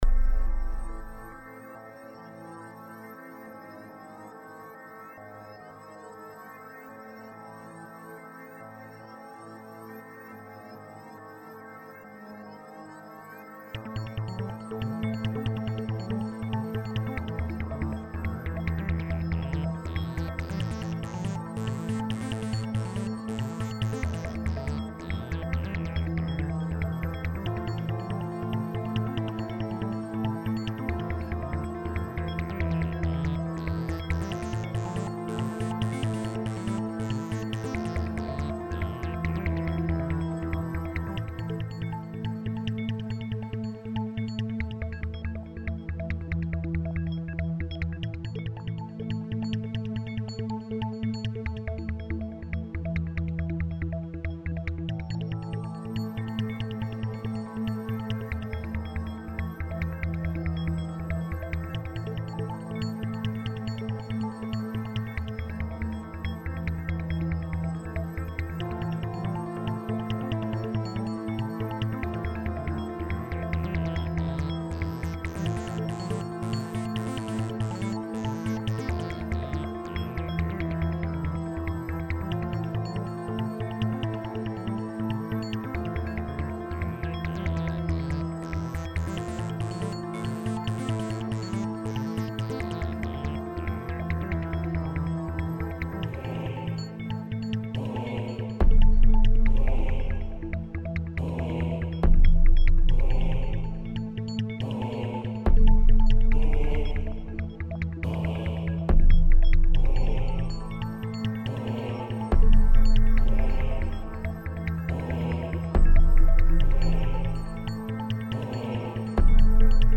Space Ambient Suspense